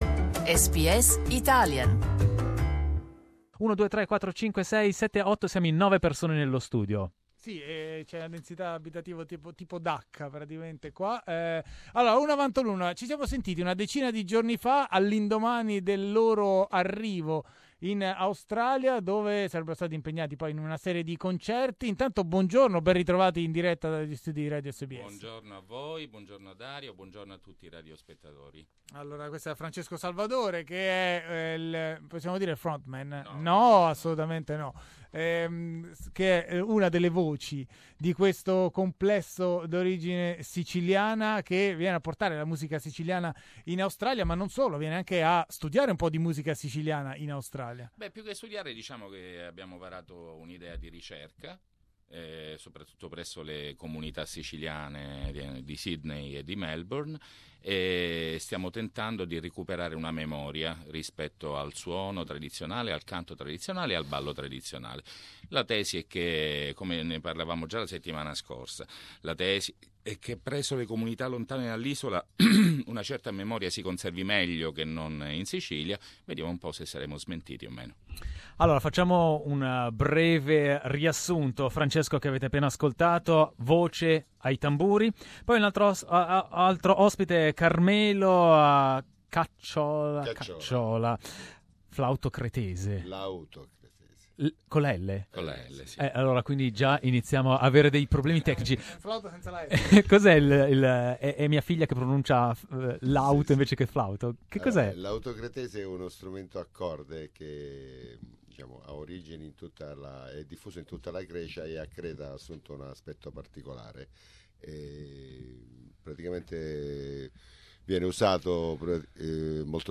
e ci ha dato uno splendido assaggio di musica dal vivo.
voce, frame drums
voce, zampogna, sicilian flute
chitarra
lauto cretese
mandolino
mandoloncello